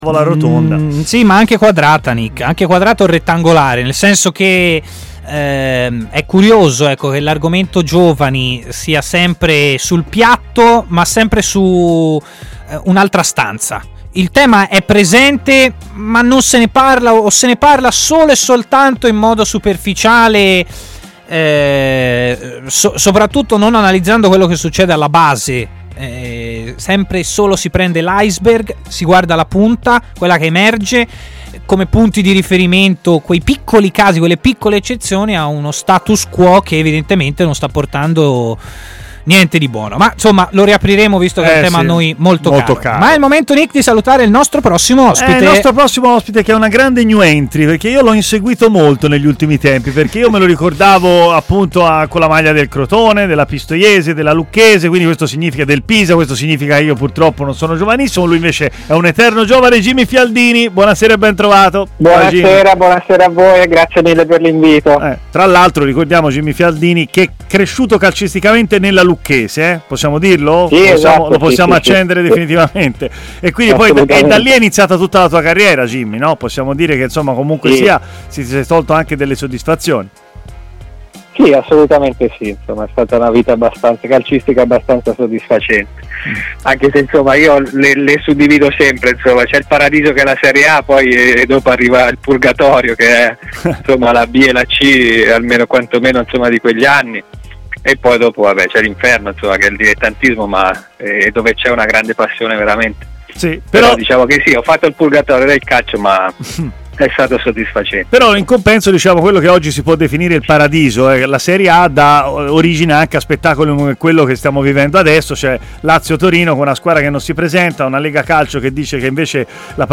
L'ex centrocampista
è intervenuto in diretta su TMW Radio, nel corso della trasmissione Stadio Aperto